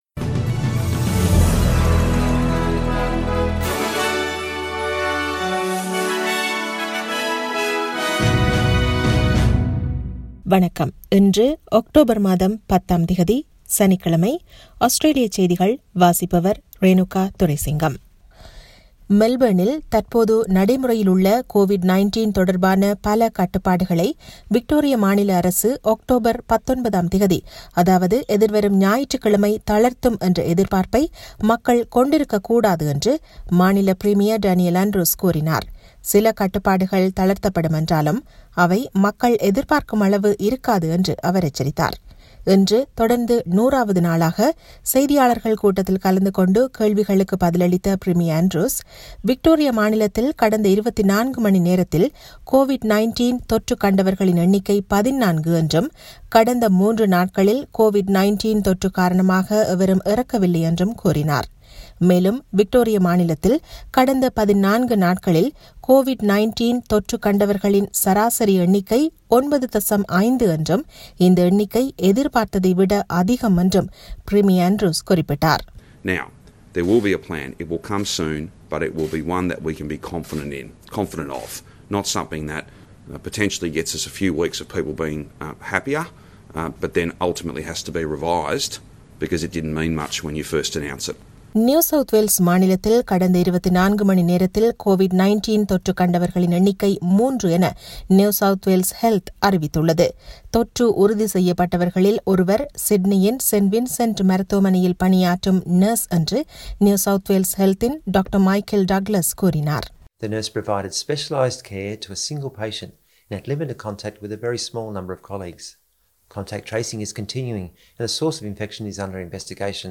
Australian news bulletin for Saturday 10 October 2020.